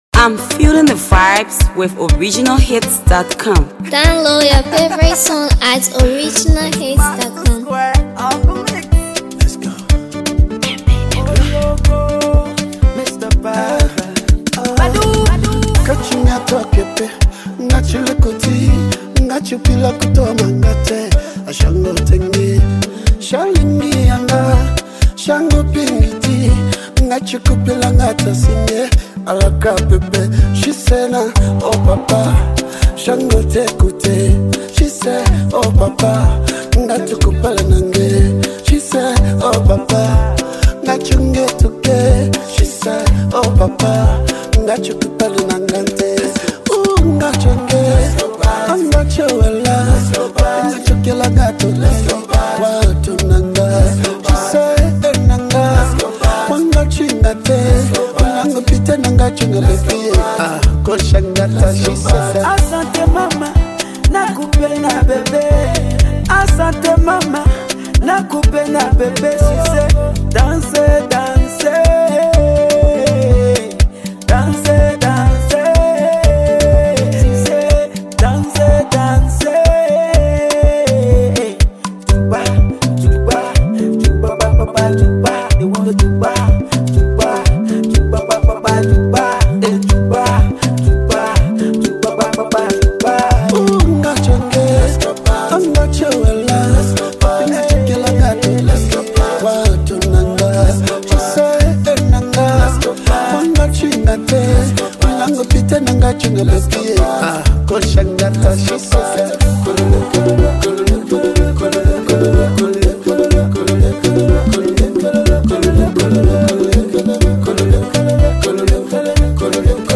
the talented Liberian singer and songwriter
vibrant tune
is a certified danceable jam.